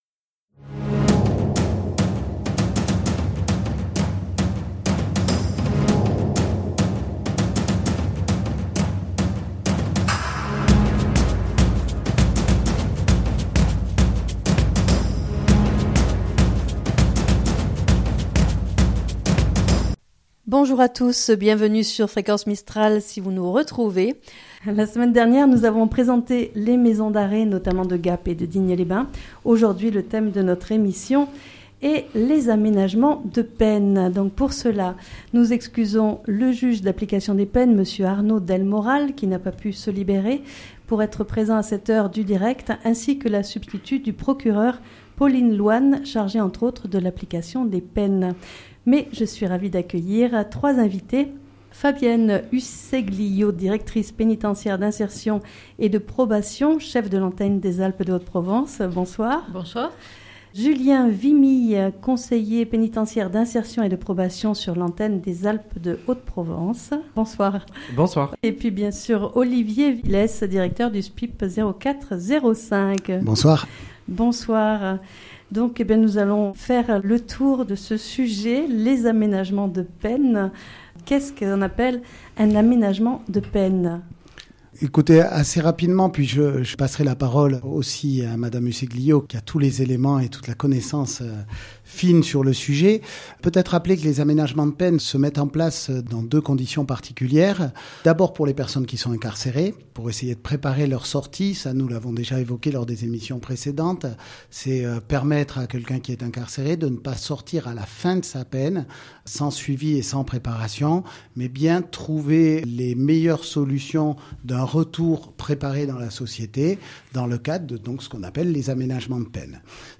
Dans le cadre des émissions que nous réalisons sur nos systèmes judiciaire et pénitentiaire, c’est notre 3ème rendez-vous sur les ondes de Fréquence Mistral. Cette émission est consacrée à l'aménagement de peine.